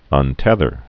(ŭn-tĕthər)